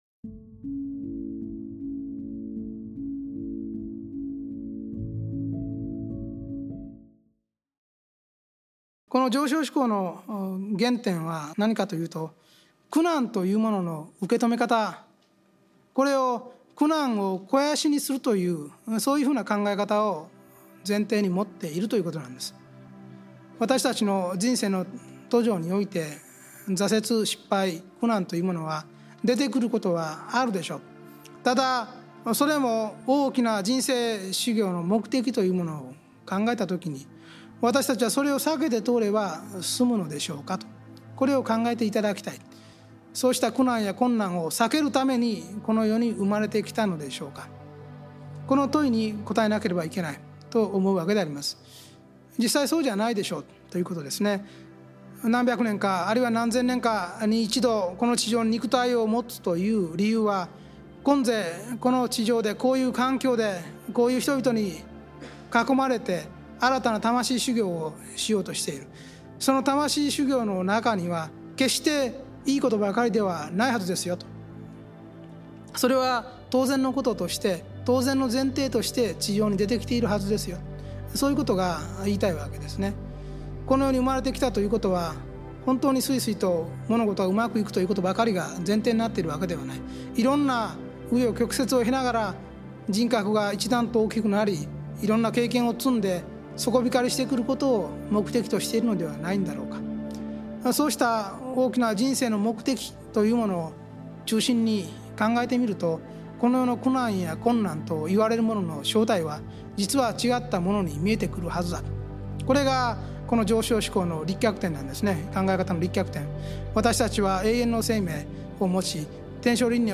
ラジオ番組「天使のモーニングコール」で過去に放送された、幸福の科学 大川隆法総裁の説法集です。